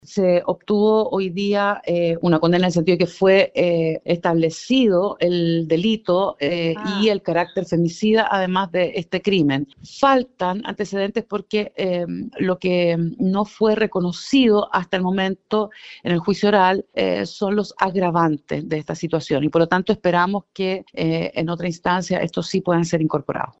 Al respecto, Francisca Pérez, directora regional del Servicio Nacional de la Mujer y la Equidad de Género (SernamEG), valoró el fallo, señalando que constituye un avance en materia de justicia reparatoria, especialmente tomando en cuenta que el organismo fue parte querellante en la causa.